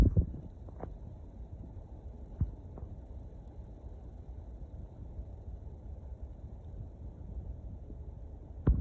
J’ai ce bruit récurrent sur l’unité intérieure split Atlantic.
Bruit turbine split Atlantic
bruit-turbine-split-atlantic.mp3